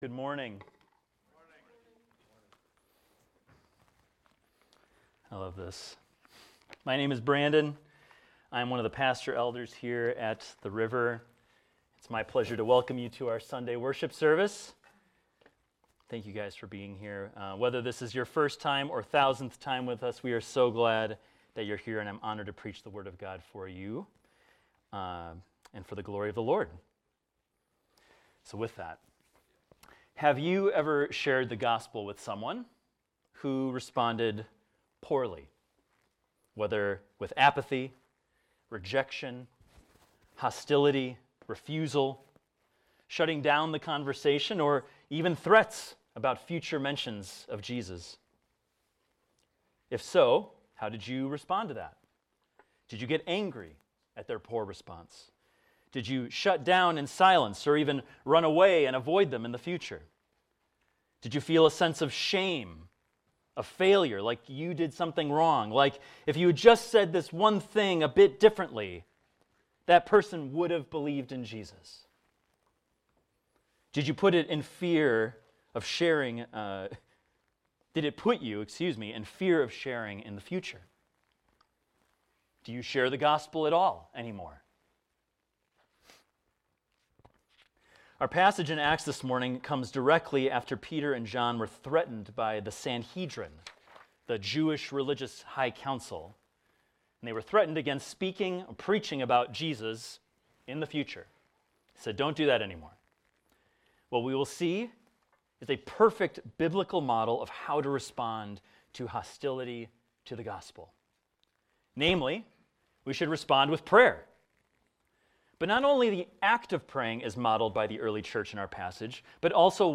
This is a recording of a sermon titled, "Pray for Boldness."